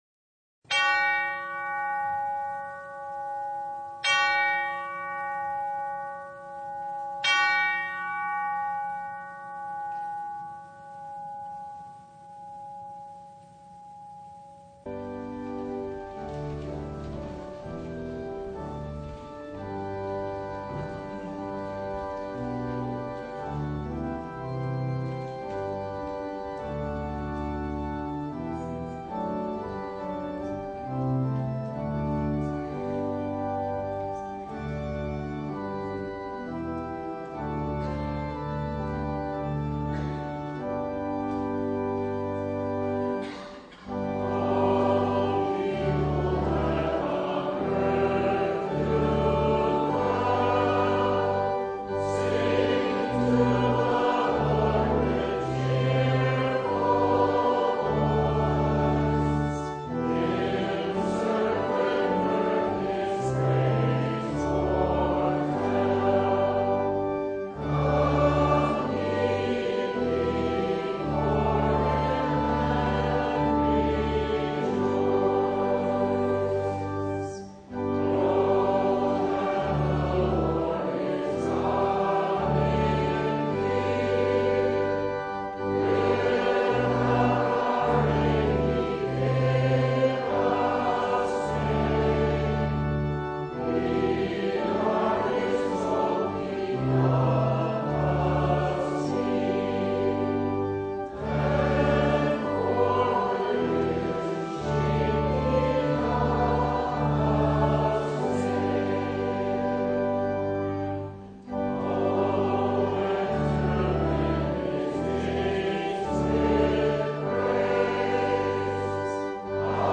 Passage: Hebrews 12:4-17 Service Type: Sunday
Full Service